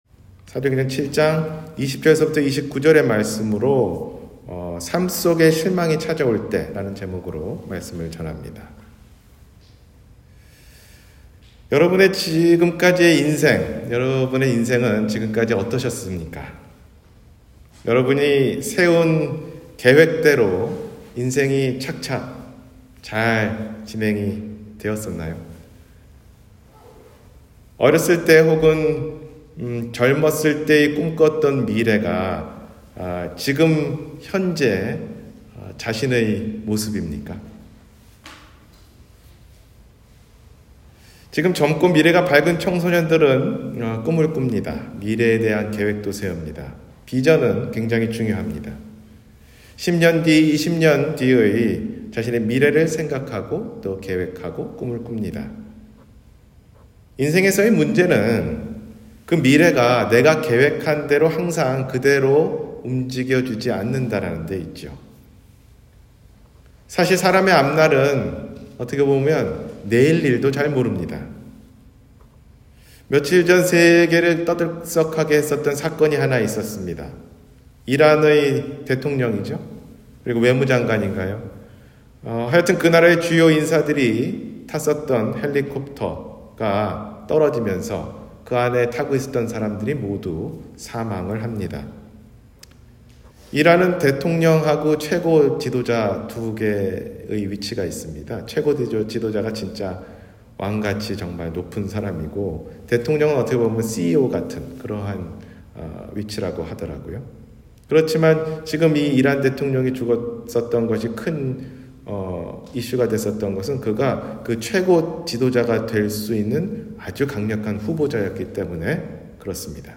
삶에 실망이 올 때 – 주일설교 – 갈보리사랑침례교회